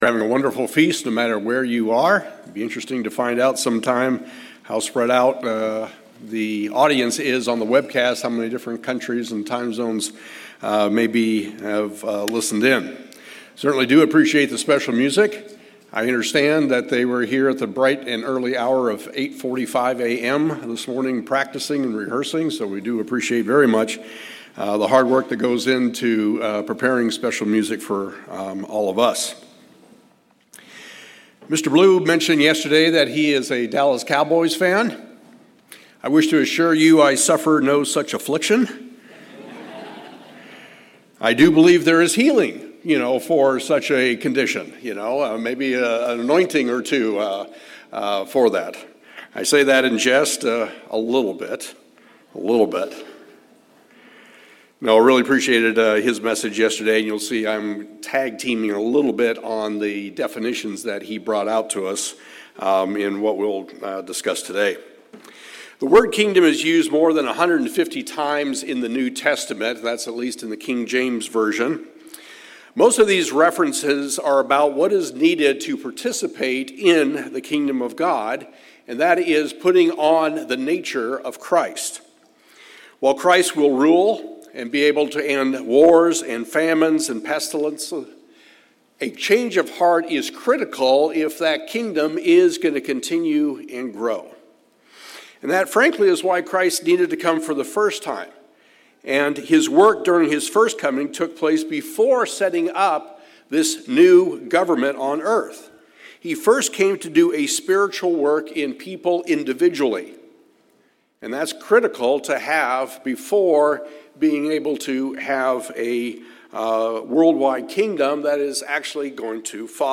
Given in Morehead City, North Carolina